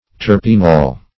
Search Result for " terpinol" : The Collaborative International Dictionary of English v.0.48: Terpinol \Ter"pin*ol\, n. [Terpin + L. oleum oil.]